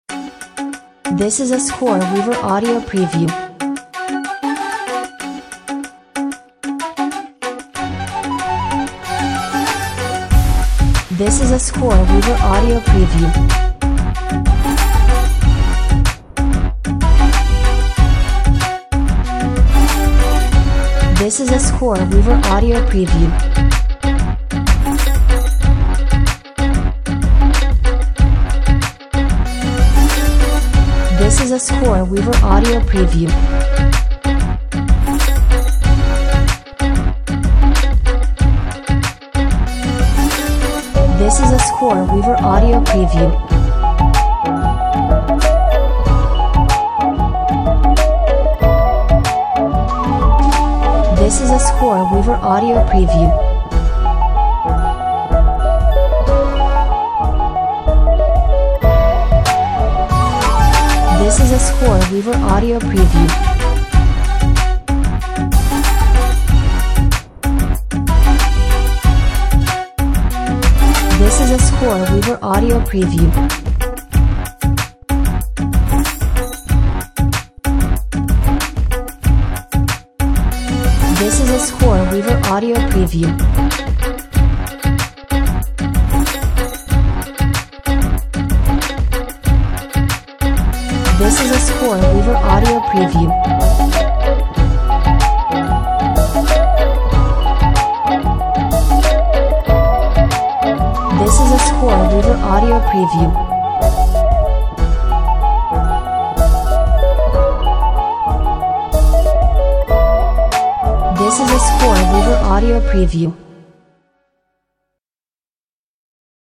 Arabian influenced Hip Hop with lots of ethnic elements.